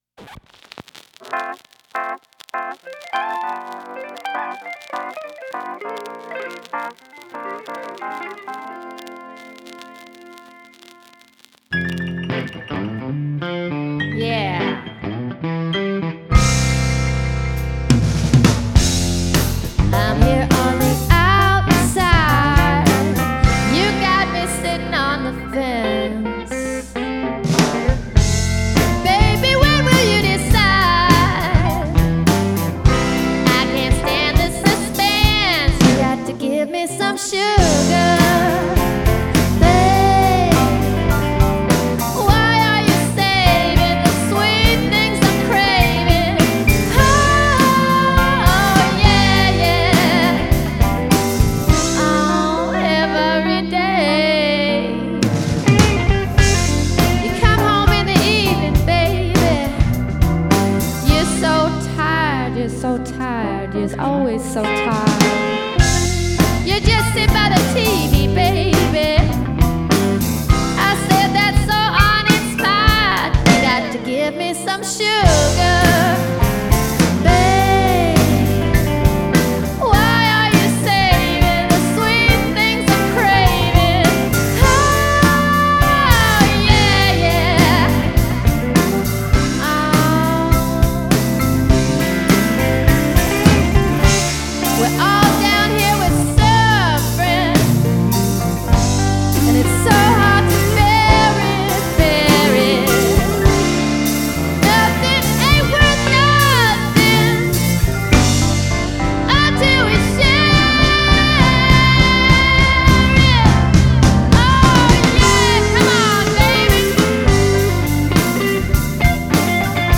blues/roots